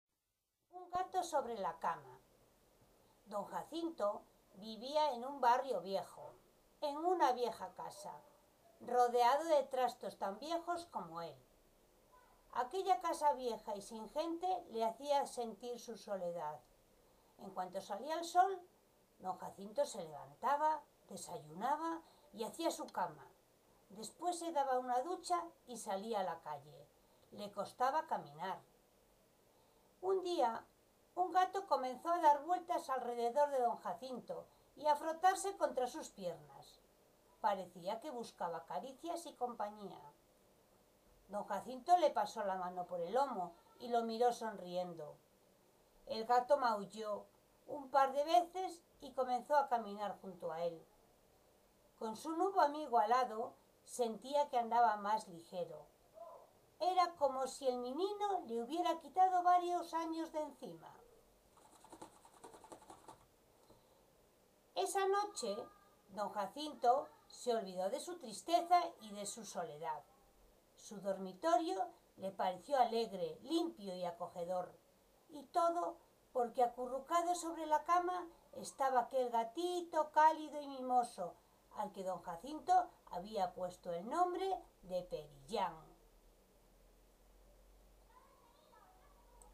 Lectura_pagina_30.mp3